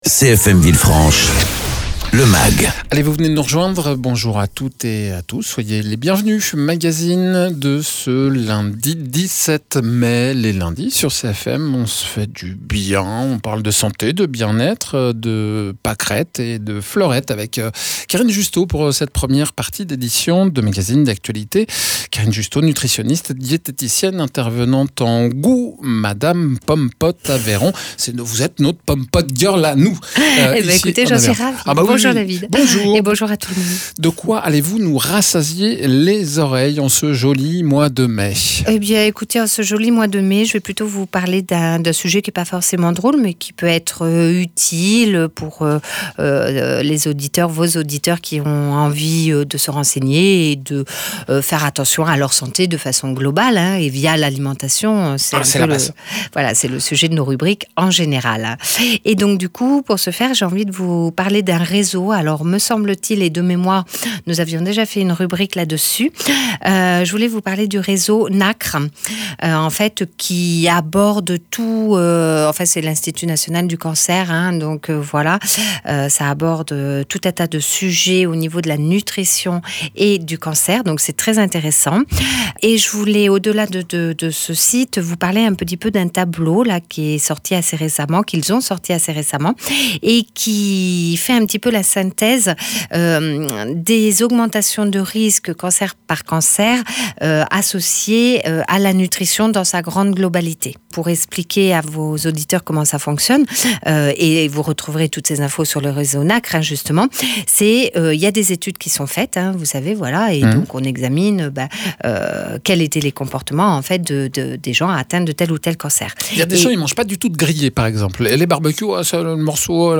nutritionniste diététicien
naturopathe